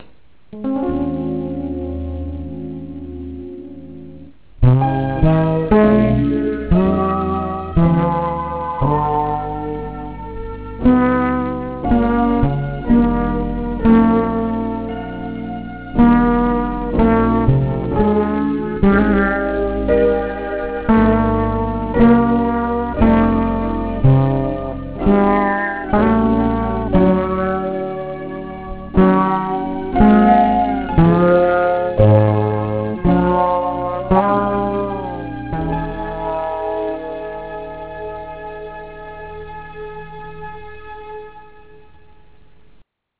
49 seconds of Who Could Be Blue ...bowed !